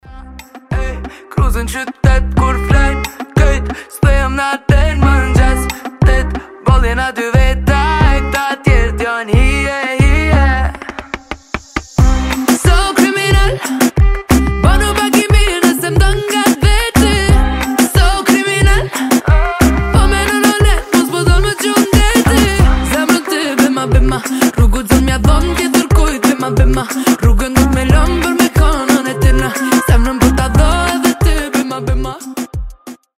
танцевальные , рэп
moombahton , ритмичные